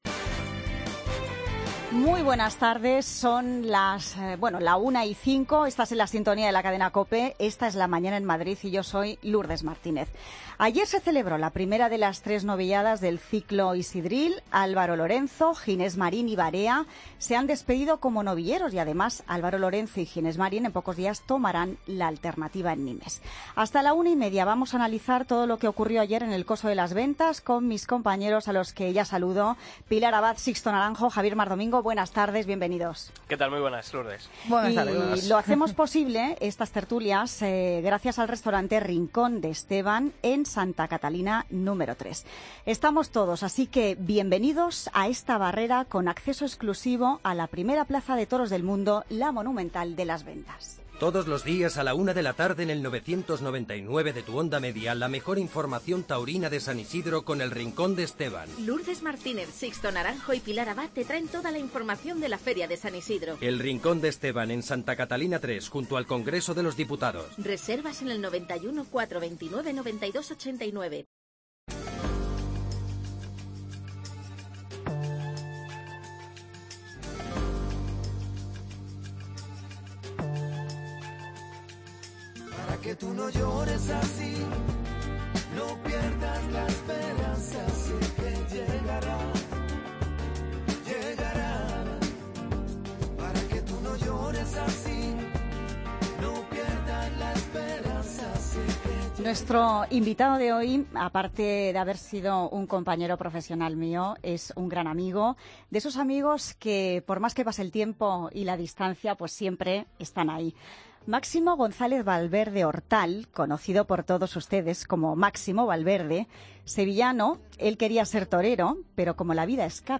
Tertulia Taurina Feria San Isidro COPE Madrid, martes 10 de mayo de 2016
AUDIO: Hoy nos ha acompañado Máximo Valverde